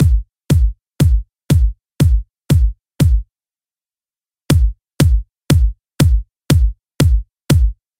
Load in the envelope shaper plugin (found in the dynamics menu) and increase the attack section – you should hear a definite increase in the punch and ‘slap’ of the drum, which will really help it to cut through the mix. Here’s a before and after: